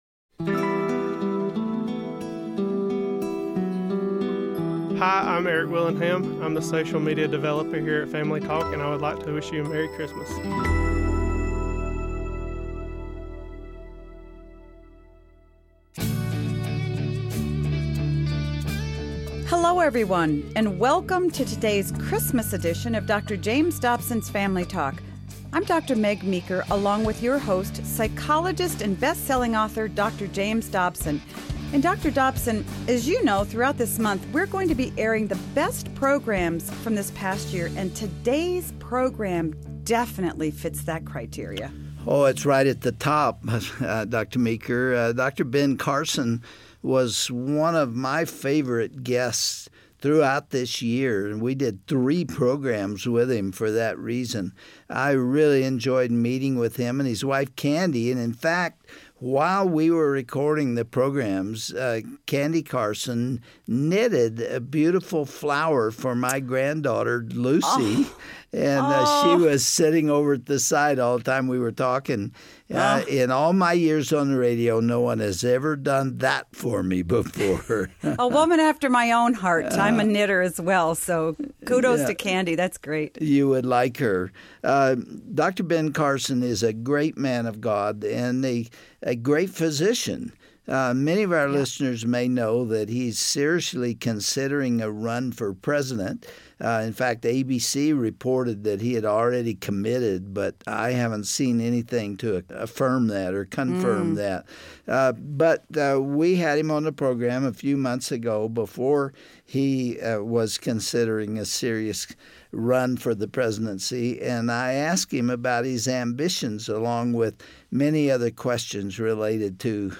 Dr. James Dobson interviews world renown pediatric neurosurgeon Dr. Ben Carson about his story and the future of America.